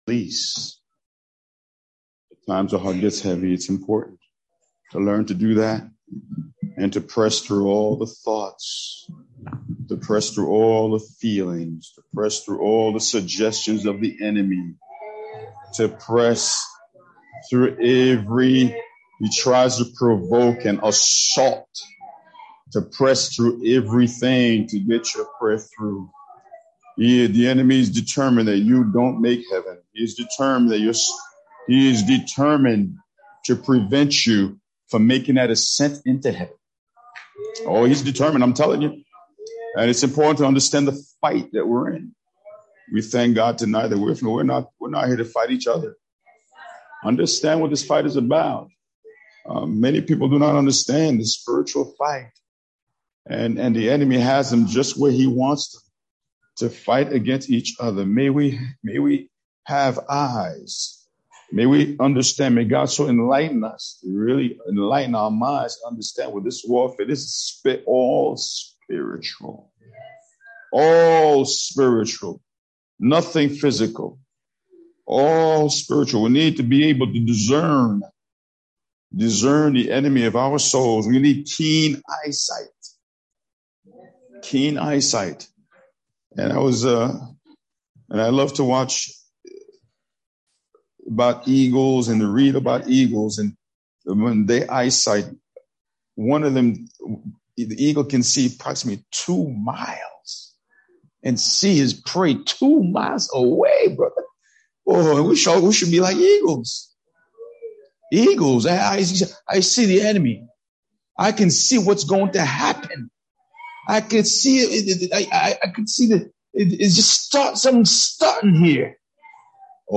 Sermons - Arverne Church of God